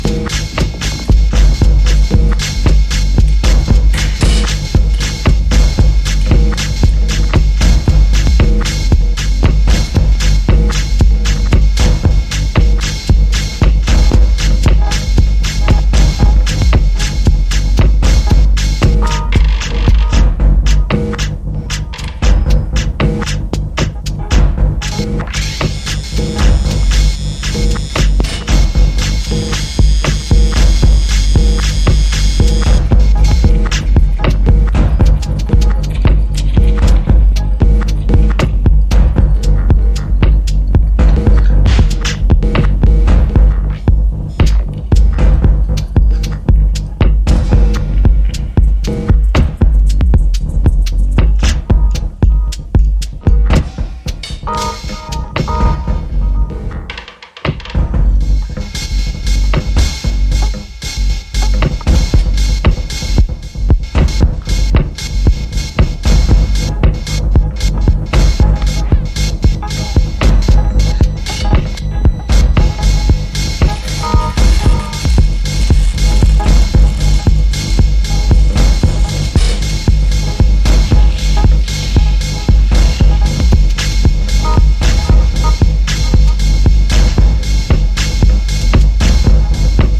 Electronix Techno 10inch
superb weirdness